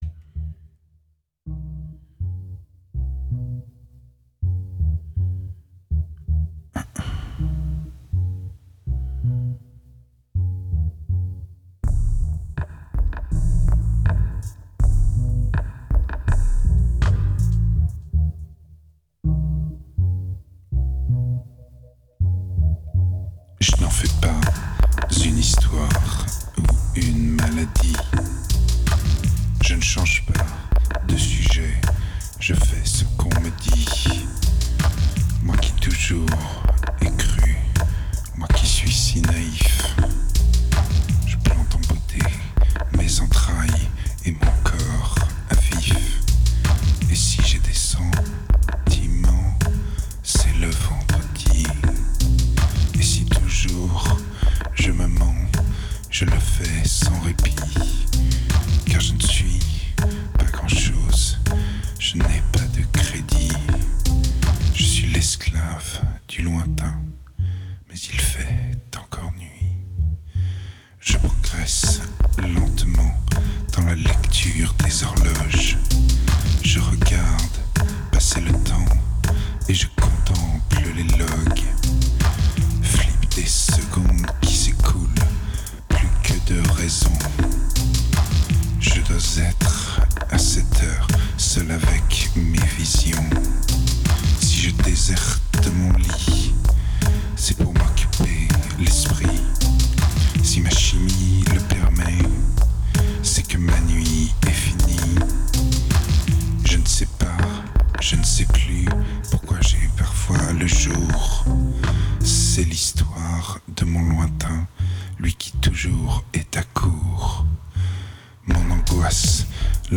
2516📈 - 5%🤔 - 150BPM🔊 - 2009-04-25📅 - -212🌟